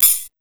FX140CYMB2-R.wav